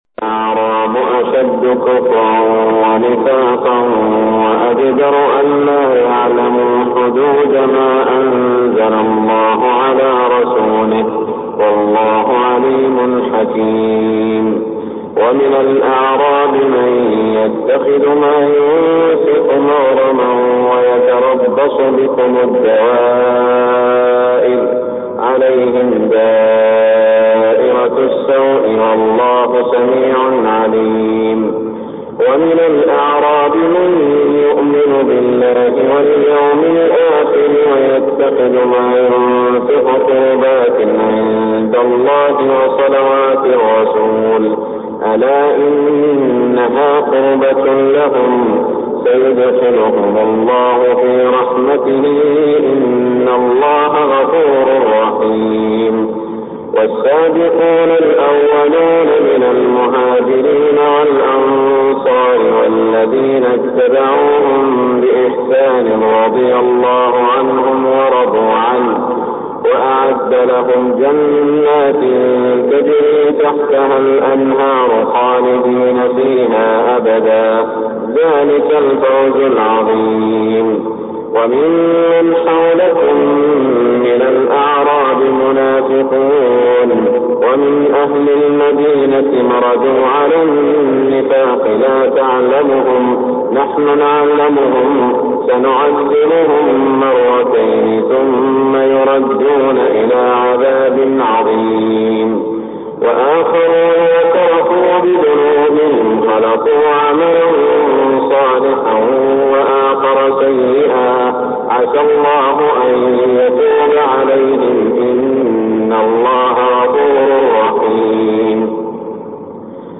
صلاة الفجر 1419هـ من سورة التوبة > 1419 🕋 > الفروض - تلاوات الحرمين